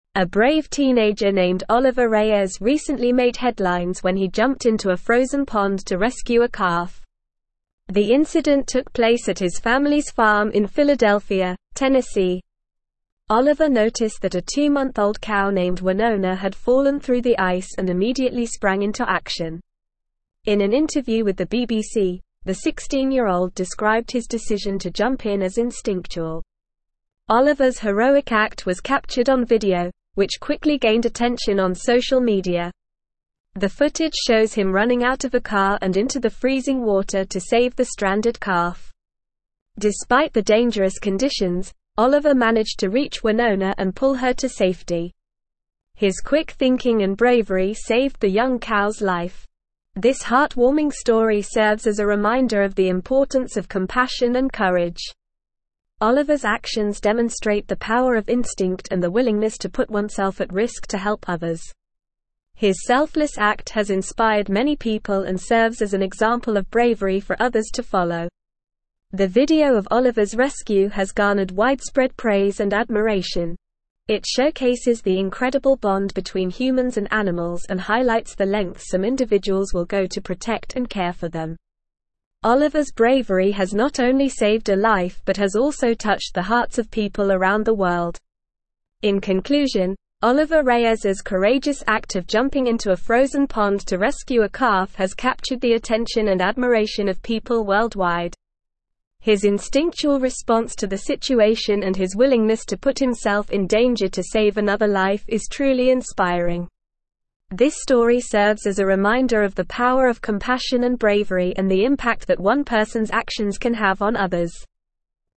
Normal
English-Newsroom-Advanced-NORMAL-Reading-Teenager-Rescues-Longhorn-Calf-from-Frozen-Pond.mp3